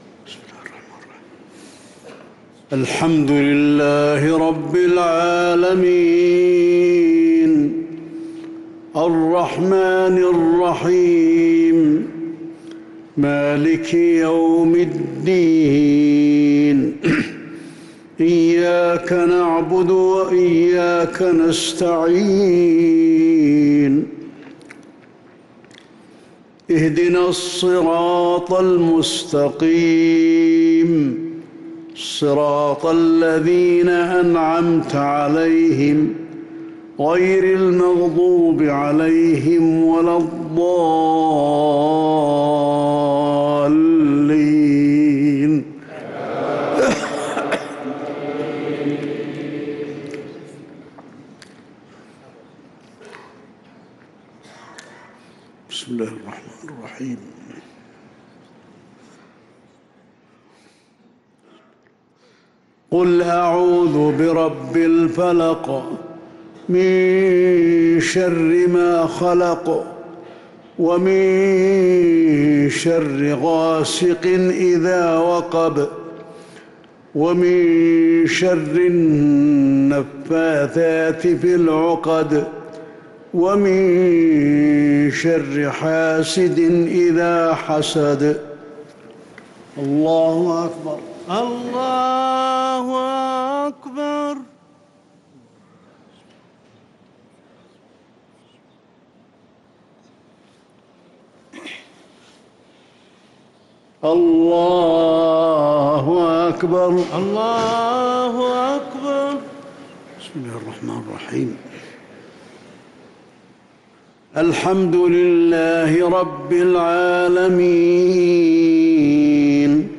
صلاة المغرب للقارئ علي الحذيفي 14 رجب 1445 هـ
تِلَاوَات الْحَرَمَيْن .